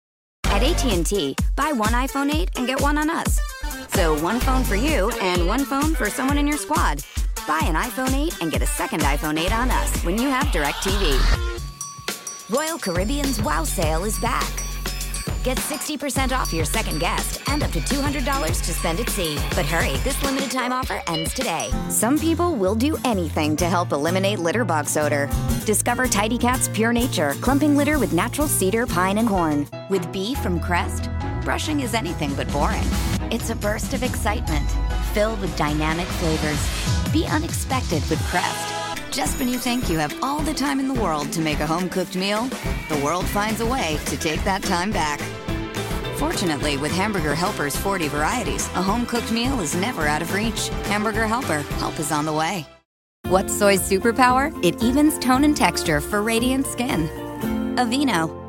Commercial Demo (Audio)
Young Adult